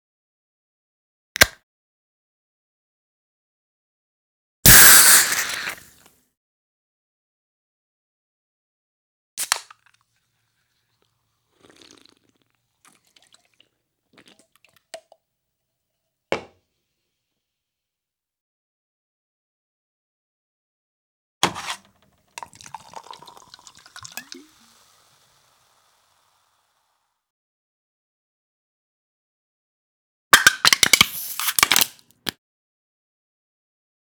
household
Can Soft Drink Noise